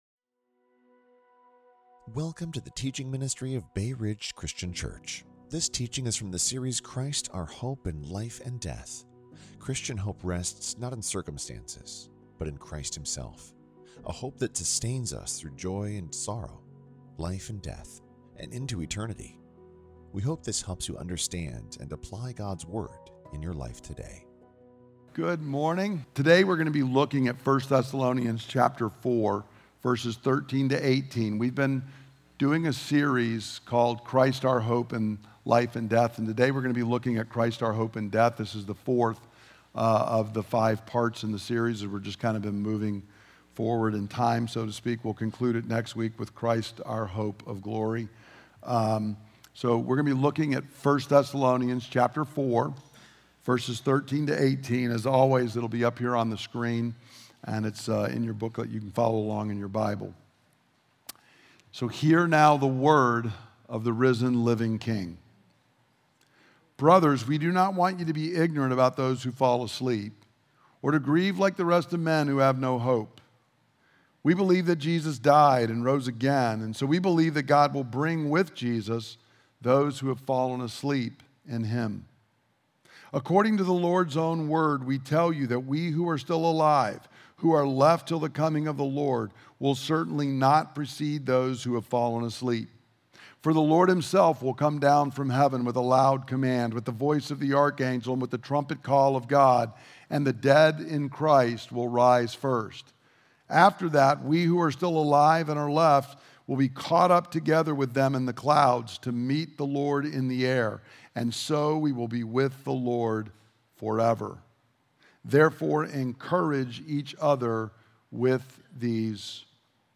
Listen to the teaching – Join us on Facebook or Youtube Live on Sunday @ 10:00 am